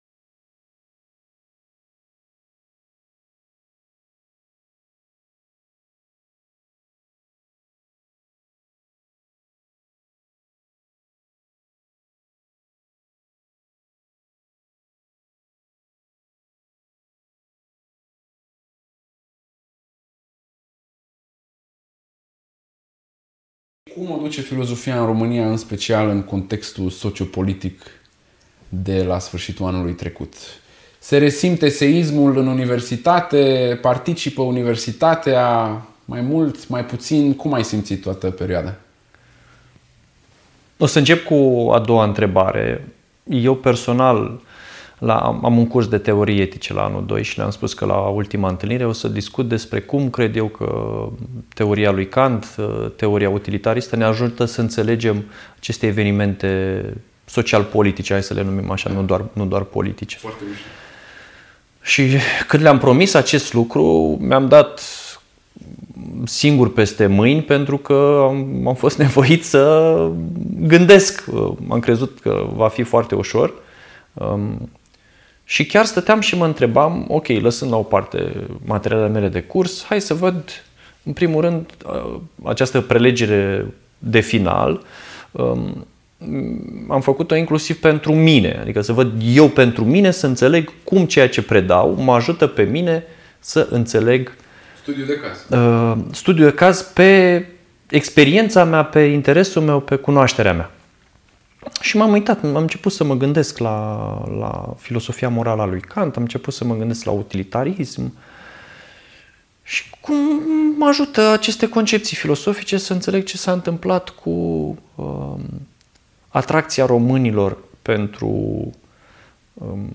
A ieșit o conversație de care mi-am amintit cu drag după ce m-am trezit din mahmureală!